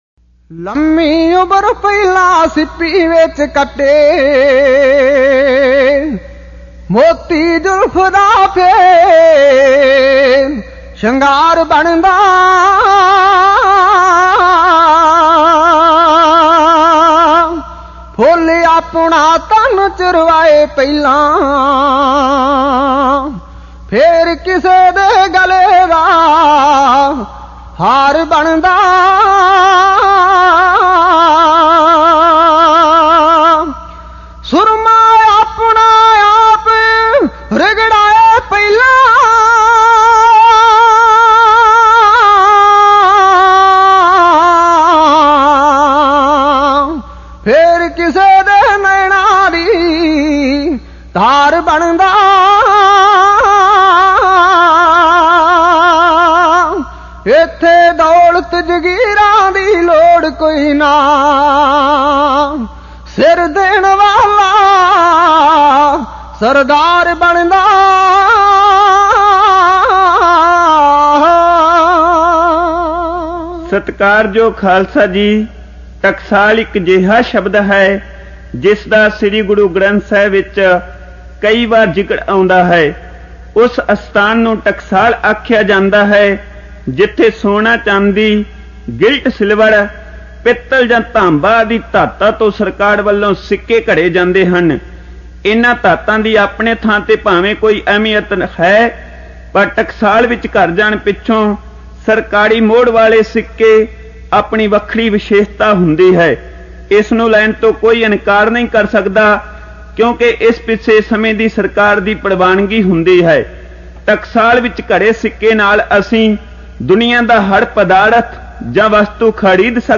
Genre: Dhadi Vaara